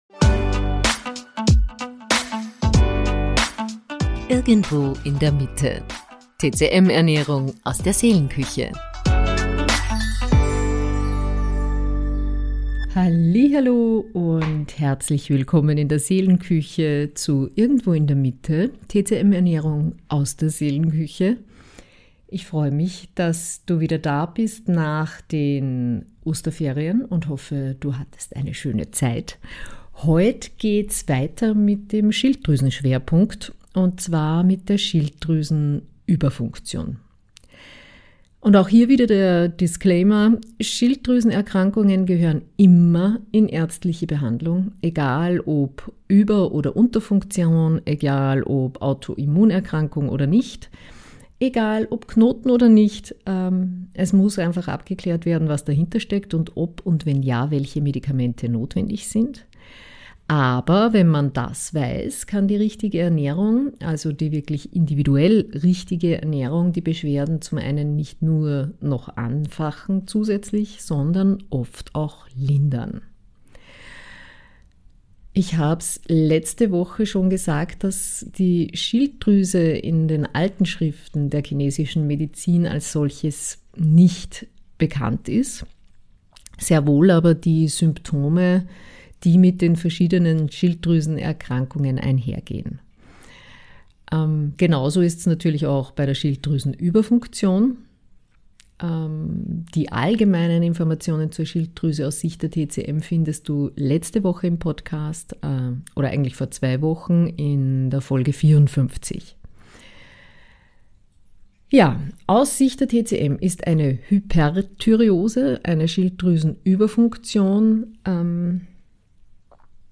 PS: Die Nebengeräusche, die hin und wieder vorkommen, bedeuten, dass mein Hund durchs Zimmer läuft oder sich schüttelt.